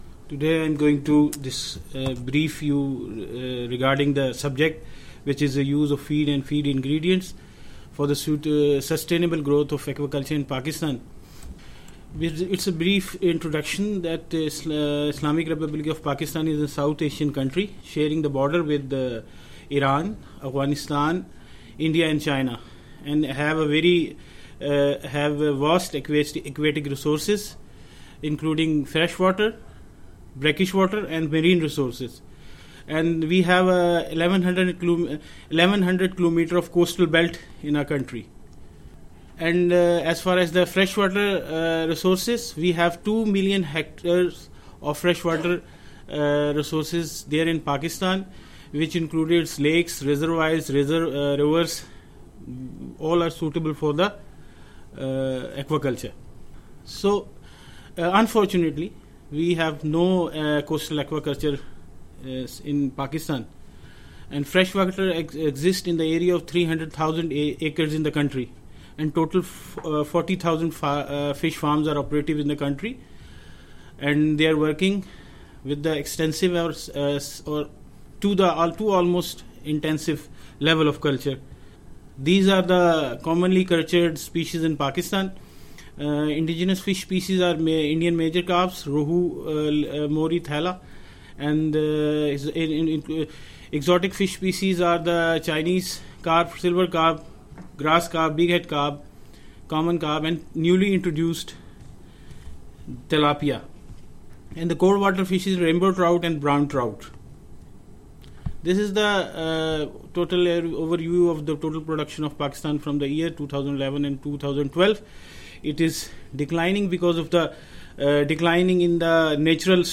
A regional consultation on aquaculture feed production and use in Asia-Pacific was held from 7-9 March 2017.
This collection contains audio recordings of the technical presentations made by experts, international organisations, the private sector and governments in the region.